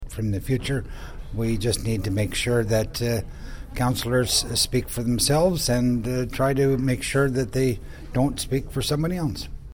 Quinte News spoke to Councillor Thompson after the meeting.